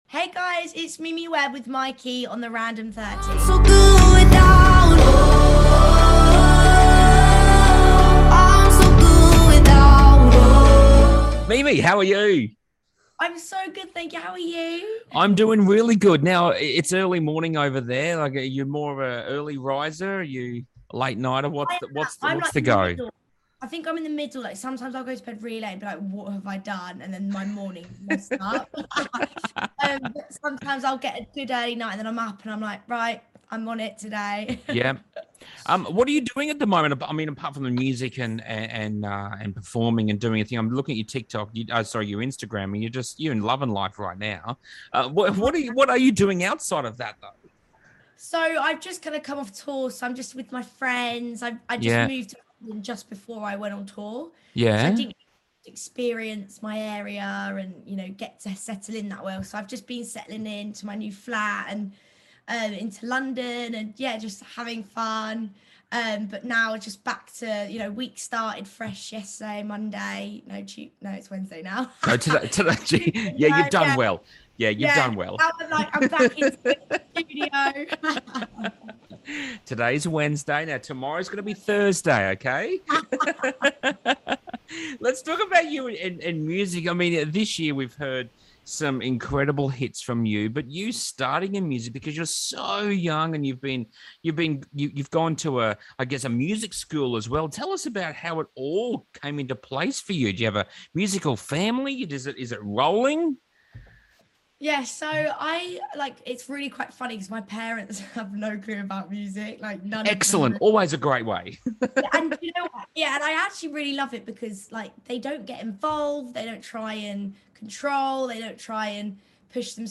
Mimi Webb Interview